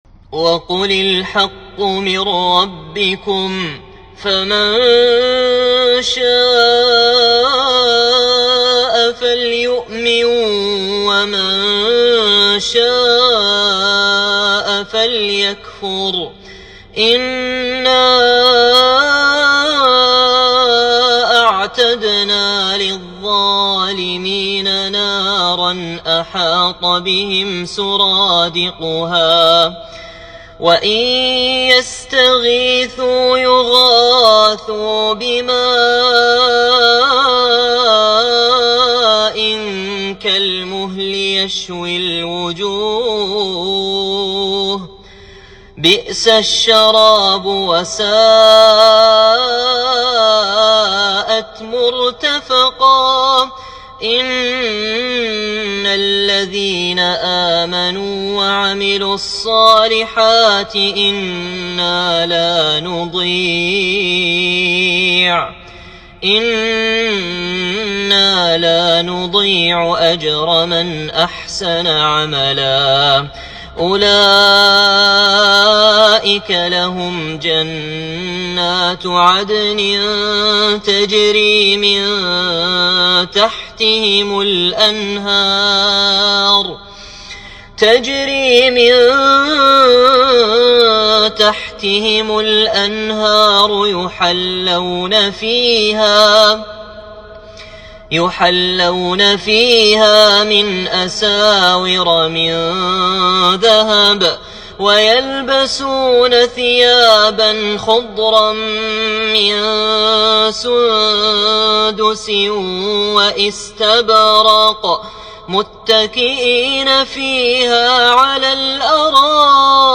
تلاوة من سورة الكهف